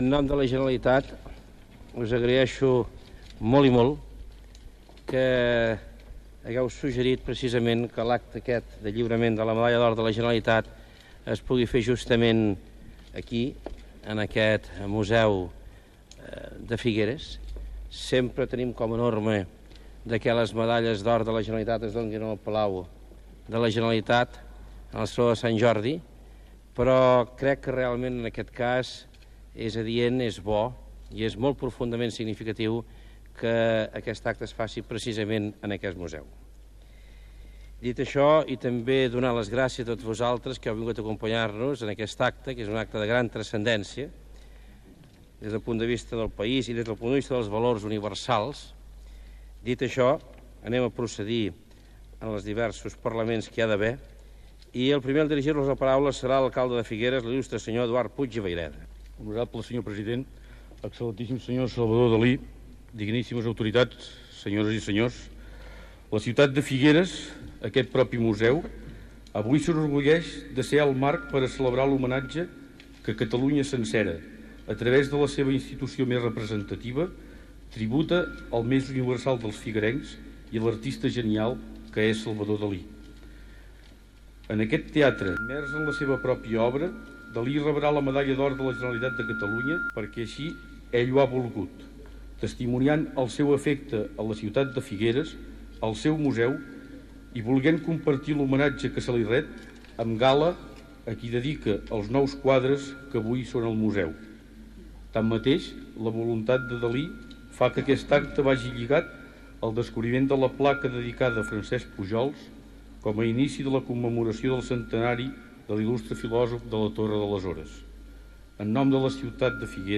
60e3b7f4afb1aeebe17d7c5509c07bff34155ef7.mp3 Títol Ràdio 4 Emissora Ràdio 4 Cadena RNE Titularitat Pública estatal Descripció Transmissió del lliurament de la medalla d'or de la Generalitat al pintor Salvador Dalí des del Museu Dalí de Figueres. Paraules del president de la Generalitat Jordi Pujol, l'alcalde de Figueres Josep Puig, de l'escriptor Josep Vicenç Foix .